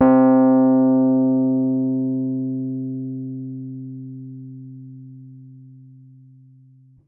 piano-sounds-dev
c2.mp3